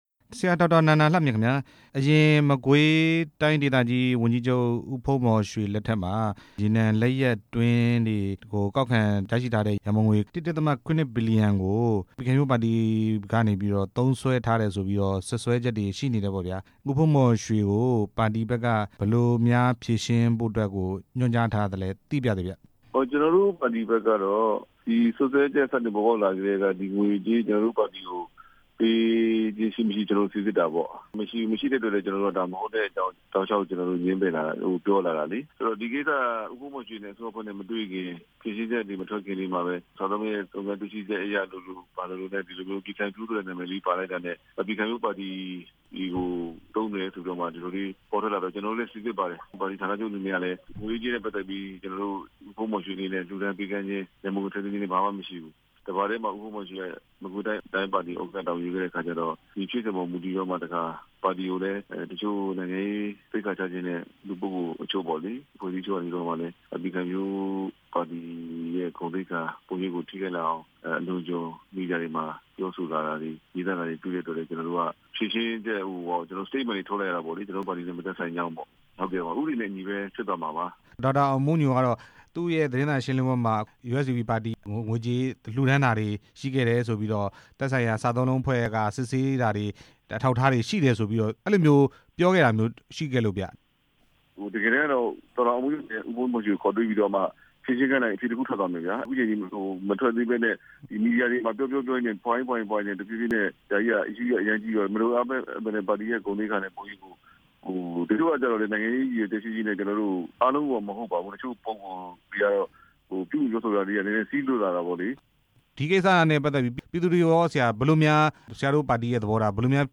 မကွေးတိုင်းဒေသကြီးရန်ပုံငွေတွေထဲက ပါတီကို ၁.၇ ဘီလီယံ လှူဒါန်း မေးမြန်းချက်